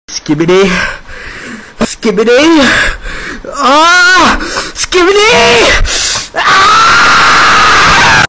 skibidi ahh sound effects